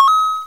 coin.ogg